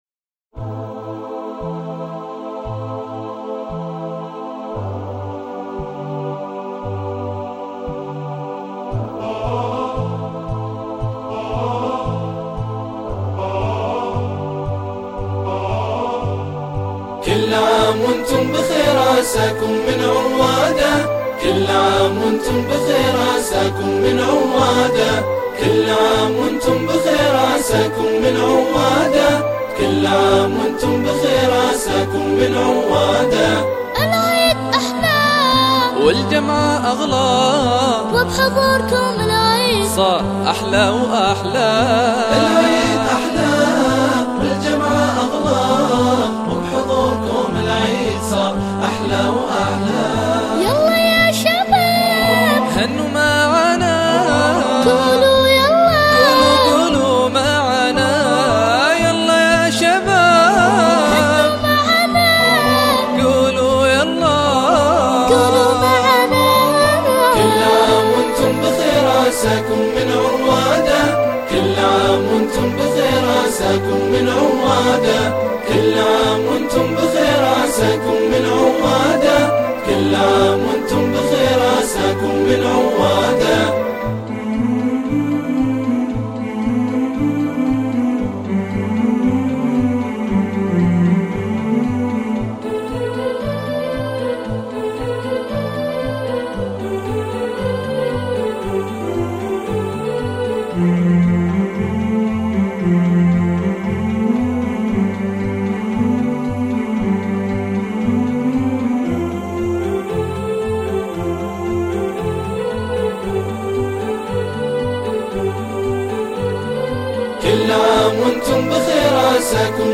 أنشودة عن العيد ..